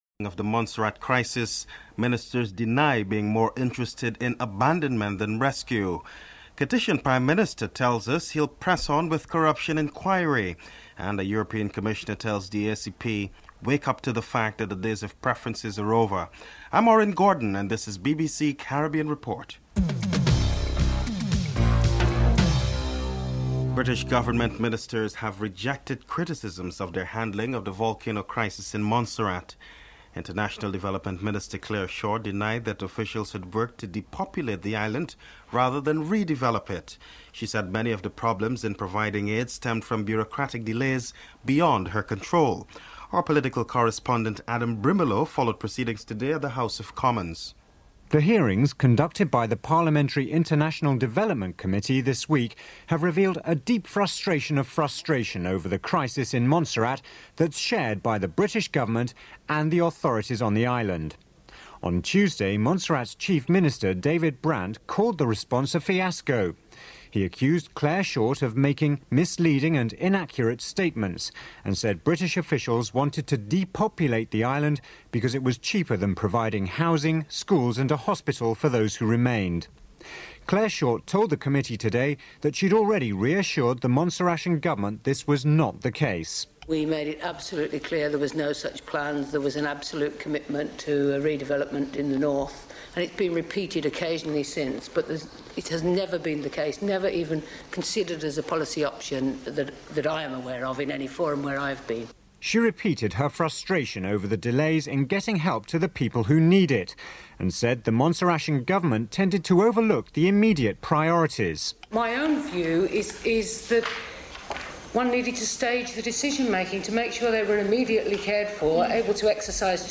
1. Headlines (00:00-00:22)
3. Kittian Prime Minister tells us that he would press on with corruption inquiry. Prime Minister Denzil Douglas is interviewed (04:38-08:54)